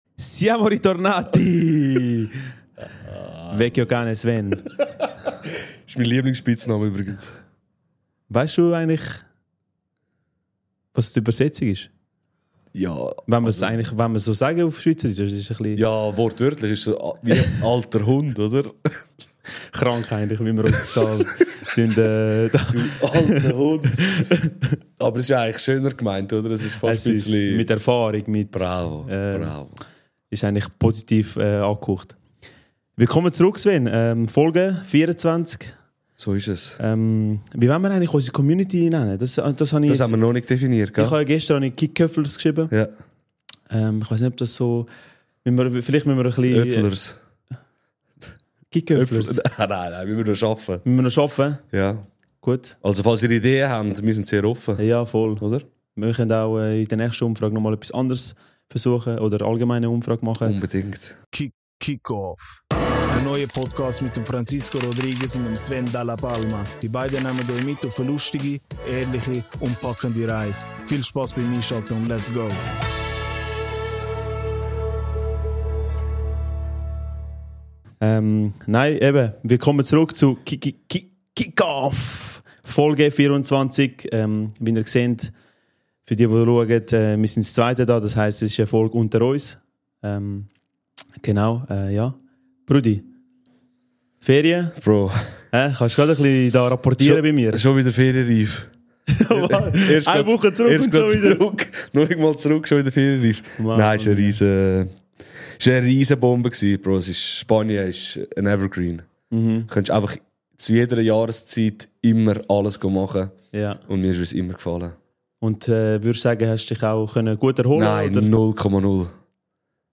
Eine Folge wie ein Gespräch nach dem Training in der Garderobe – locker, ehrlich und mit dem einen oder anderen Lacher.